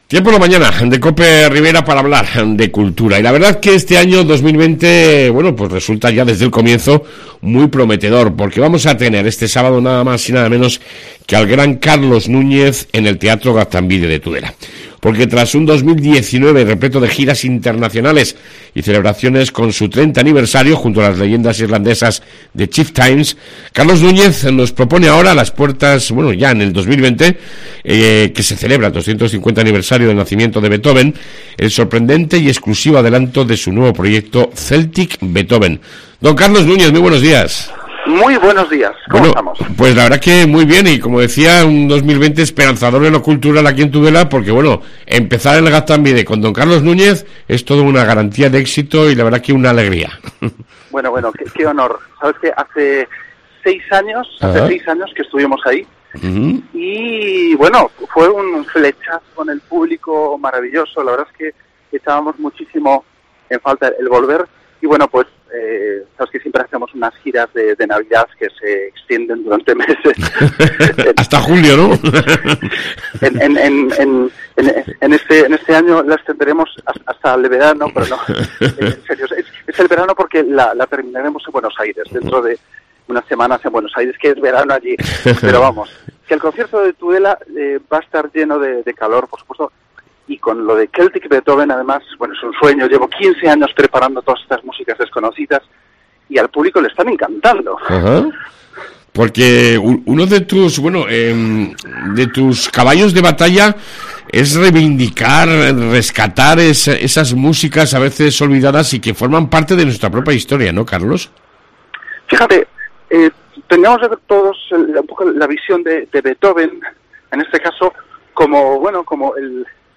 AUDIO: Entrevista con el músico Carlos Nuñez que actuará el próximo sábado en Tudela